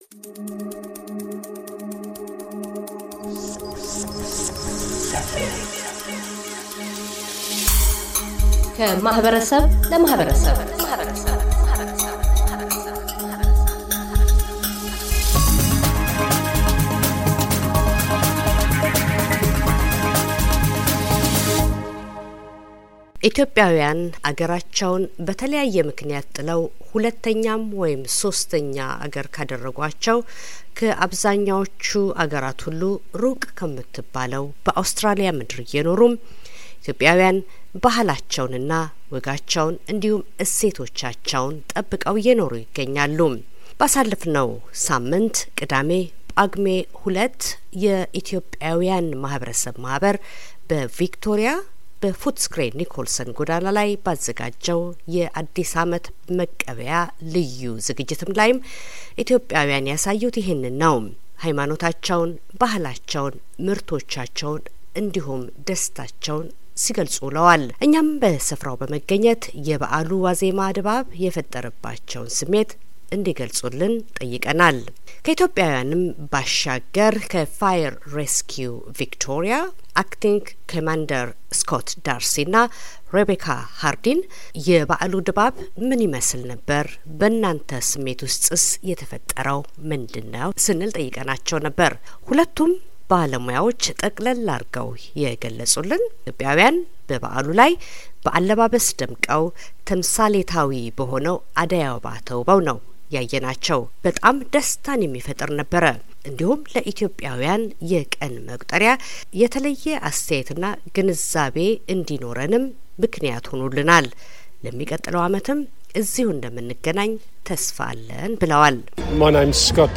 በፉትስክሬ ኒኮልሰን ጎዳና በተደረገው የ2018 አዲስ አመት መቀበያ ዝግጅት የተሳታፊዎ አስተያት እና የእንኳን አደረሳችሁ መልእክት
ethiopiam-new-year-festival-footscray-nicholson.mp3